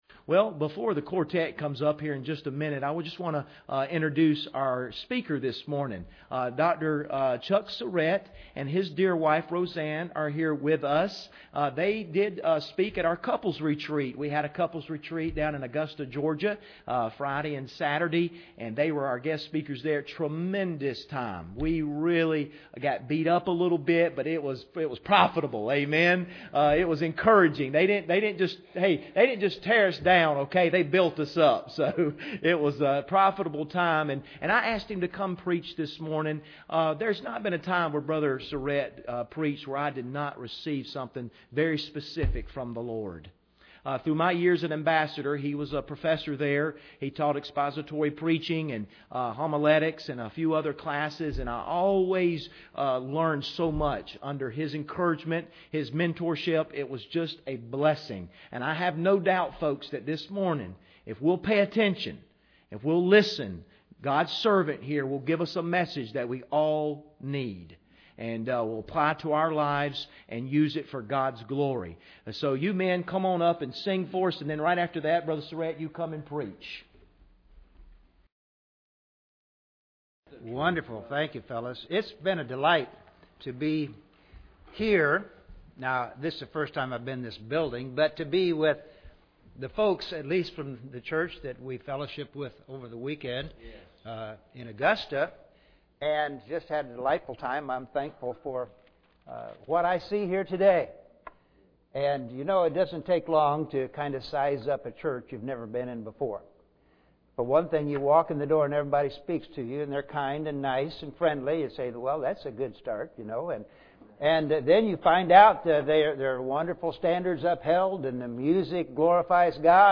Romans 2:1-2 Service Type: Sunday Morning Bible Text